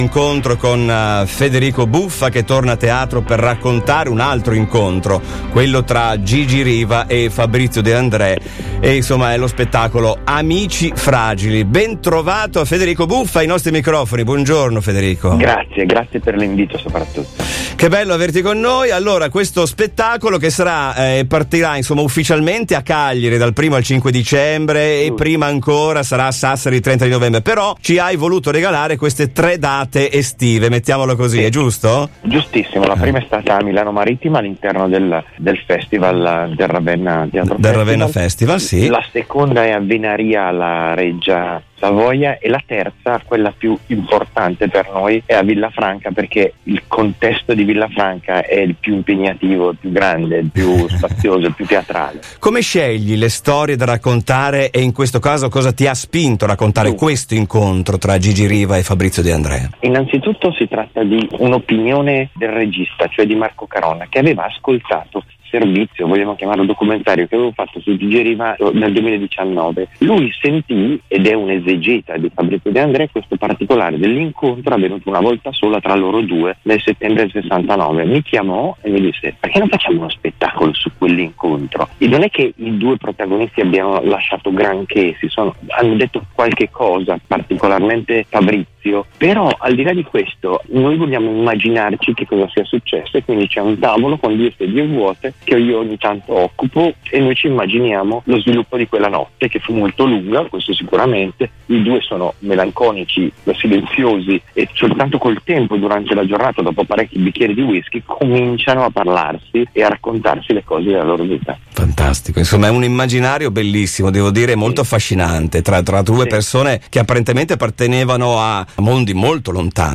L’intervista a Federico Buffa sullo spettacolo “Amici Fragili”